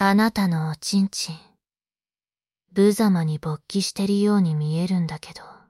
生成される音声の品質は悪くなさそうだけど喘ぎ声とかはまだうまく出せてない。
一応囁くとかのタグ効かせられる